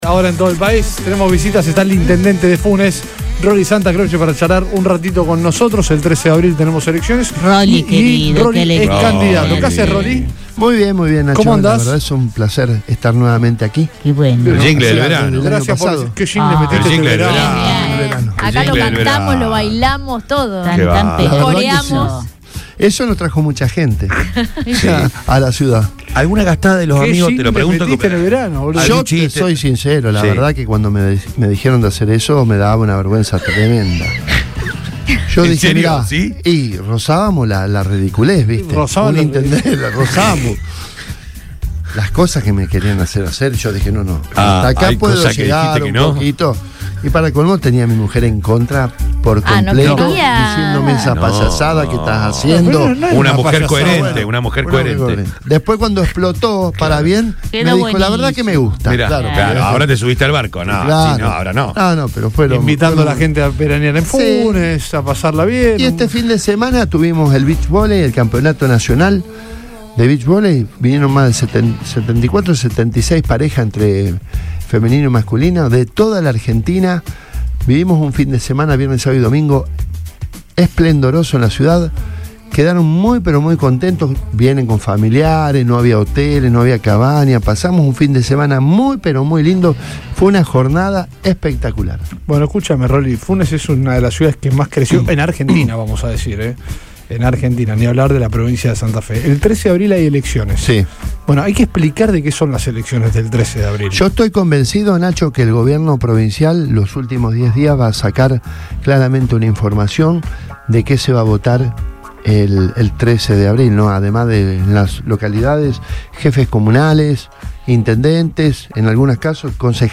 El intendente de Funes y candidato a convencional constituyente, Roly Santacroce, visitó los estudios de Radio Boing y habló con el equipo de Todo Pasa turno mañana sobre la importancia de la próxima reforma constitucional en la provincia de Santa Fe. El mandatario destacó que el 13 de abril se llevarán a cabo elecciones clave, hecho histórico ya que no ocurre desde 1982.